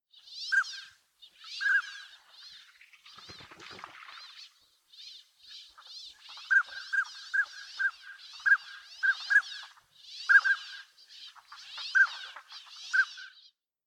オナガガモ｜日本の鳥百科｜サントリーの愛鳥活動